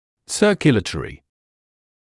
[ˌsɜːkju’leɪtərɪ], [ˈsɜːrkjələtɔːrɪ] [ˌсёːкйу’лэйтэри], [ˈсёːркйэлэтоːри] циркуляторный (об аспектах кровеностой системы); кровеносный